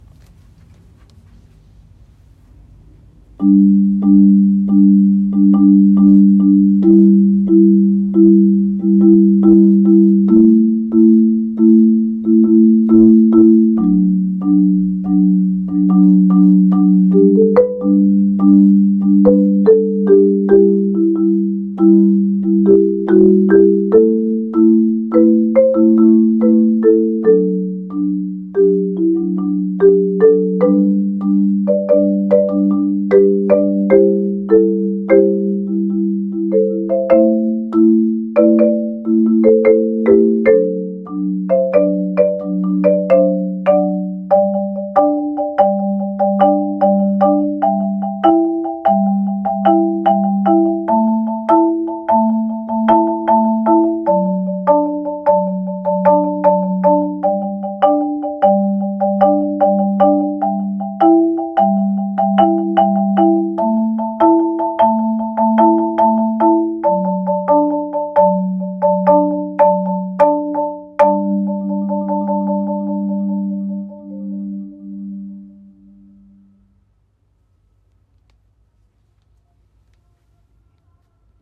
marimba solo